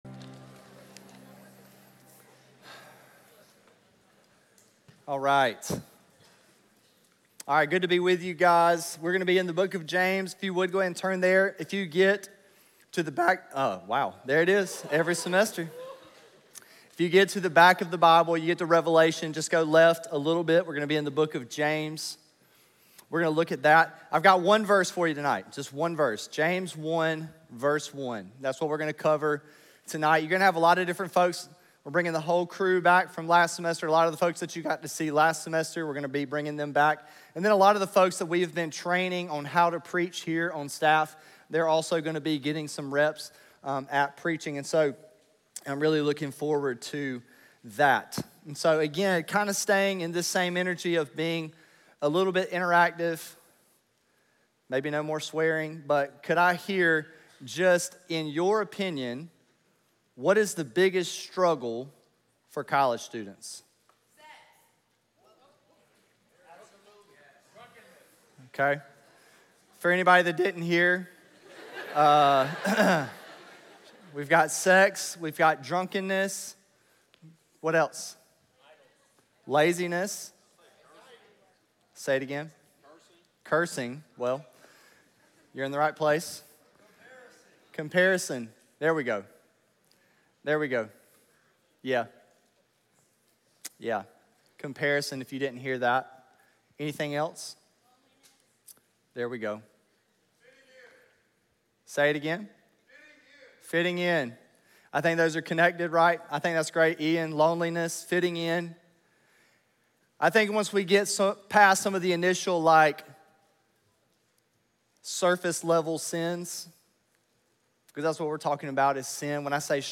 Pride doesn’t just show up as arrogance—it can look like insecurity, shame, and self-definition that quietly replaces God’s voice with our own. In this opening message from James 1:1, we’re introduced to the surprising journey of James—the brother of Jesus—who went from doubter, to convinced, to leader after encountering the resurrected Messiah.